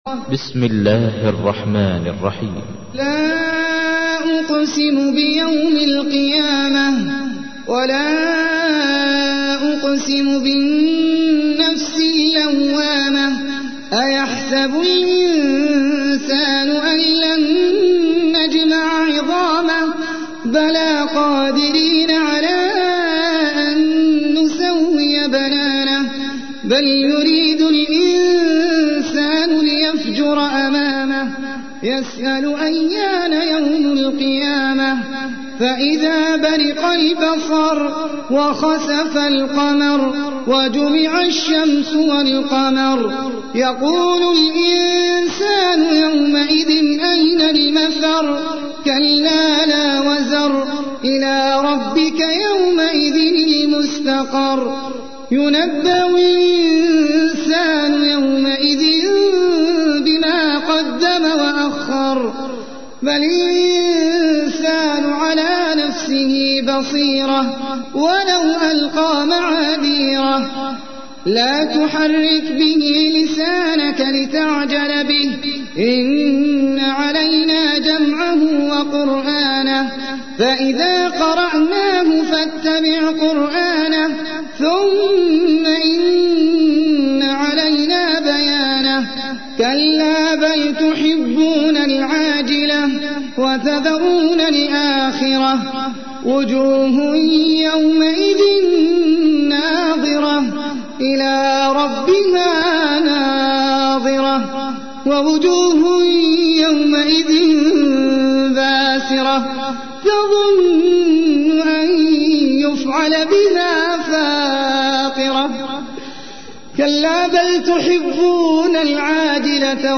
تحميل : 75. سورة القيامة / القارئ احمد العجمي / القرآن الكريم / موقع يا حسين